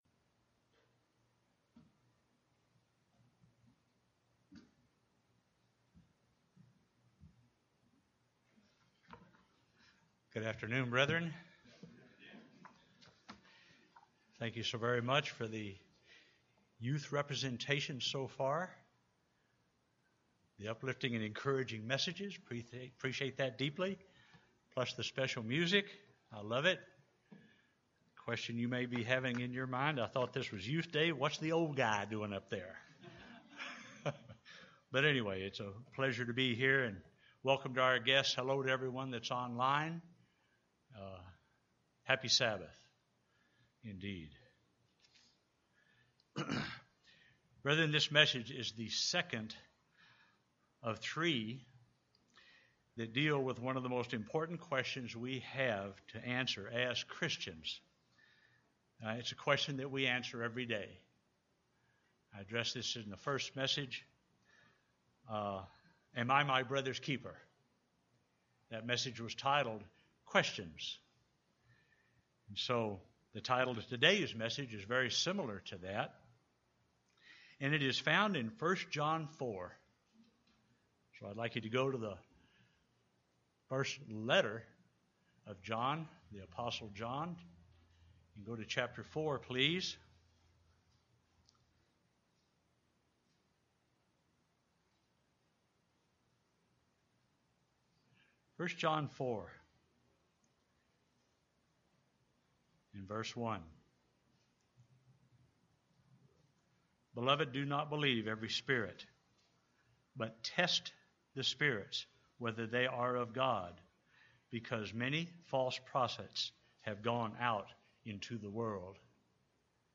This message is the second of three sermons that are devoted to answering the second most important question a human can ask.